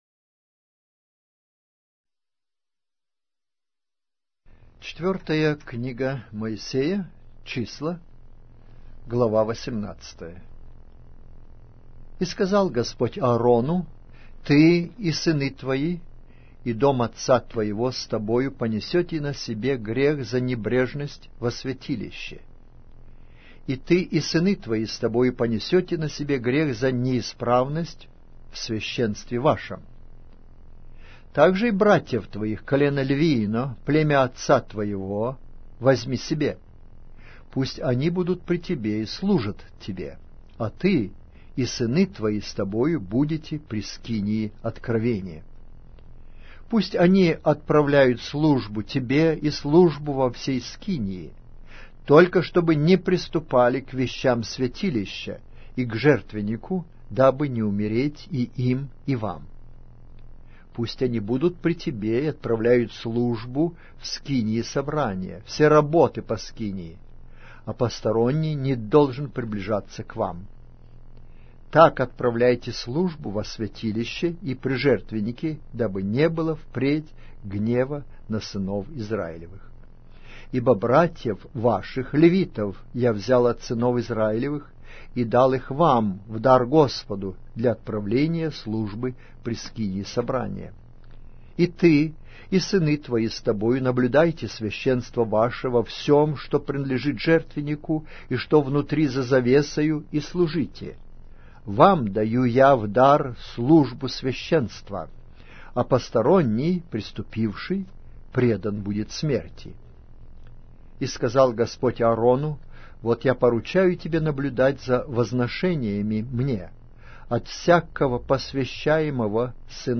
Аудиокнига: Книга 4-я Моисея. Числа